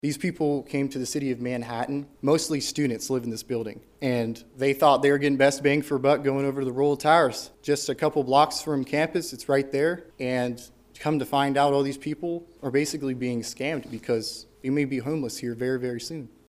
told the Manhattan City Commission Tuesday that the landlord informed he and tenants a day earlier they would not be willing to compensate for damage or evictions.